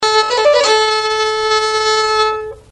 Note, at proper speed, the bubbling four note ornament (B4A4D5B4 on the note A4, sounded in the duration 1/2 a second) immediately towards the end :
last-gracing-normal.mp3